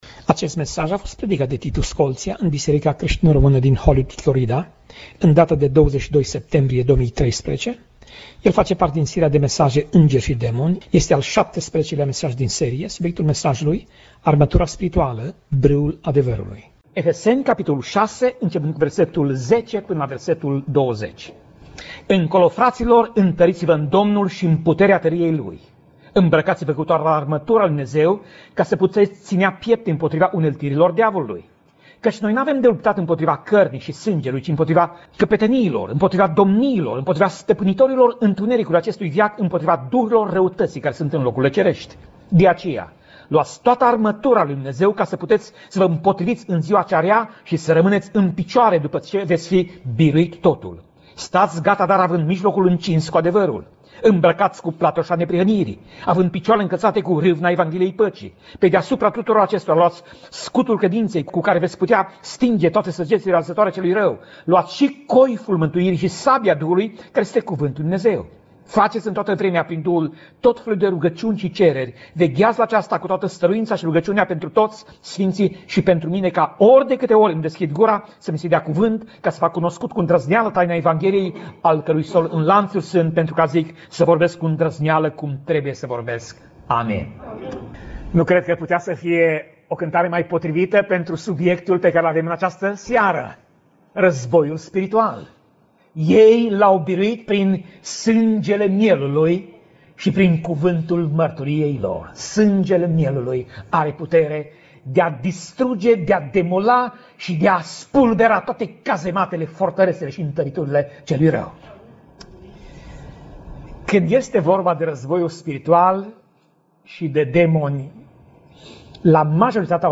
Pasaj Biblie: Efeseni 6:10 - Efeseni 6:20 Tip Mesaj: Predica